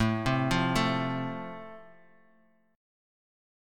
Am#5 chord